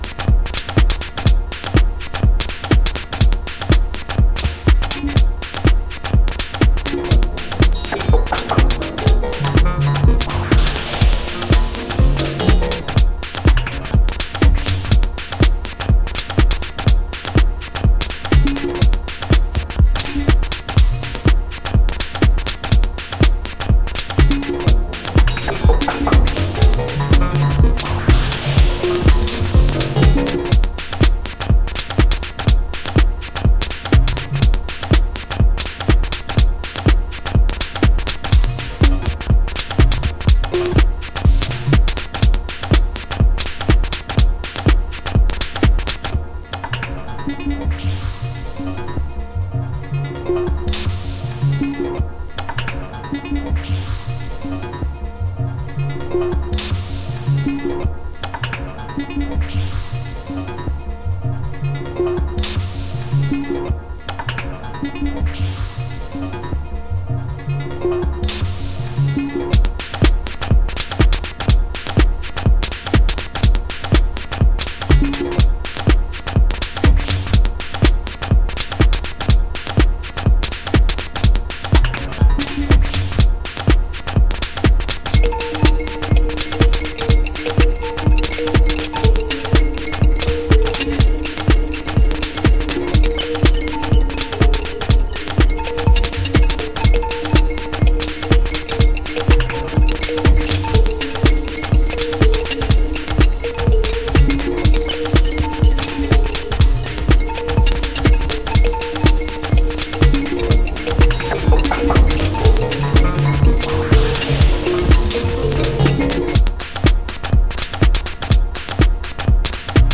ジャズサンプリングのカットアップやお得意の煌びやかなシンセを絡めた得意なサウンド。
House / Techno